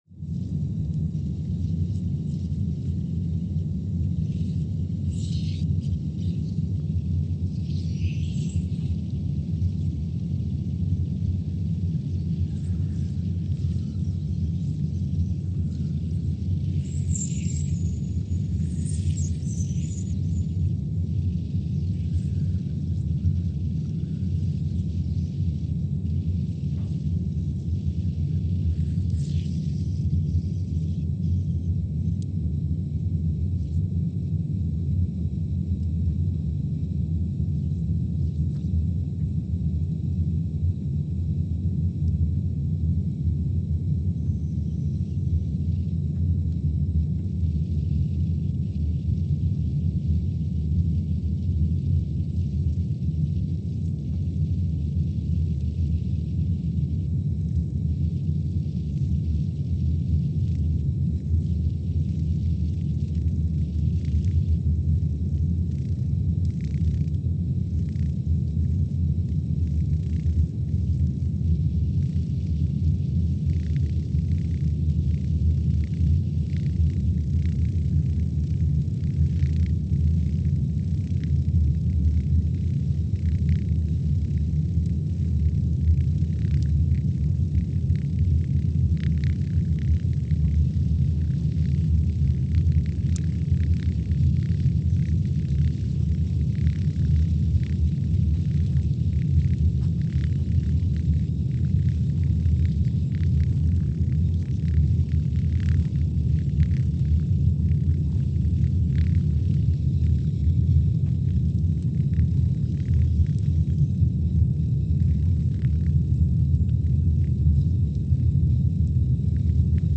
Scott Base, Antarctica (seismic) archived on March 1, 2020
No events.
Station : SBA (network: IRIS/USGS) at Scott Base, Antarctica
Sensor : CMG3-T
Speedup : ×500 (transposed up about 9 octaves)
SoX post-processing : highpass -2 90 highpass -2 90